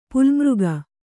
♪ pulmřga